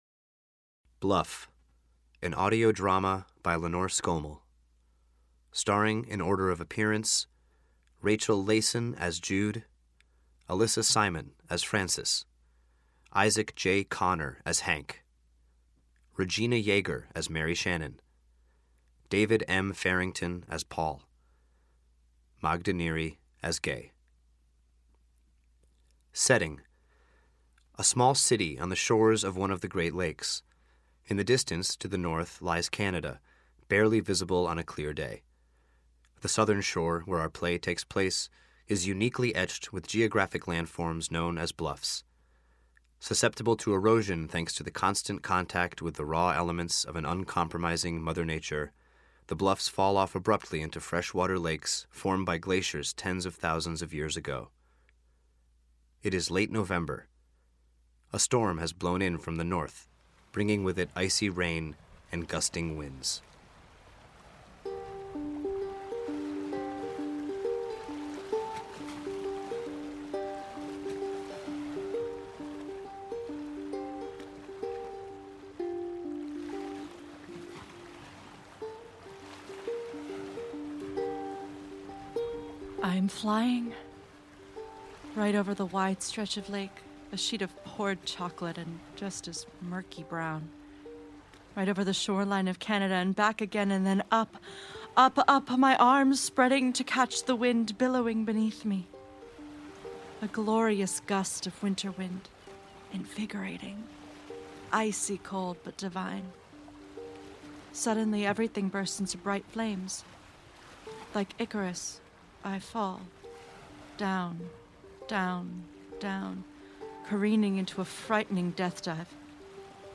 bluff.mp3